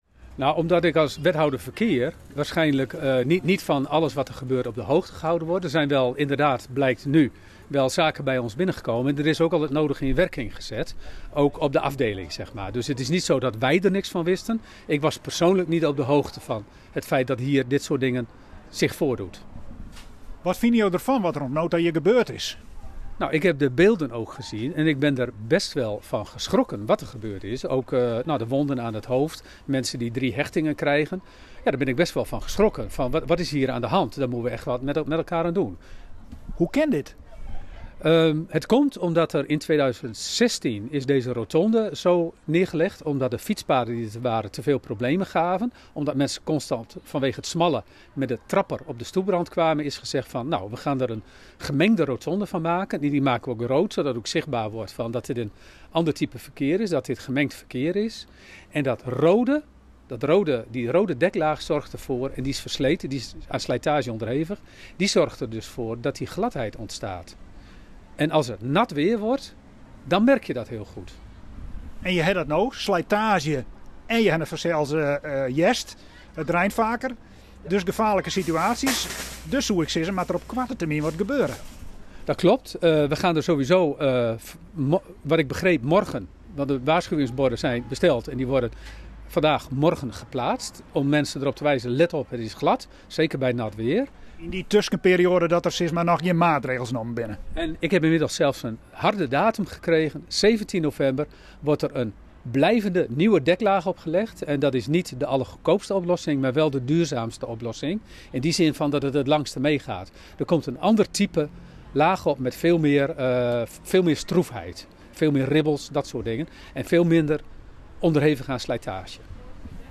Wethouder Theo Berends van de gemeente Noardeast-Fryslân: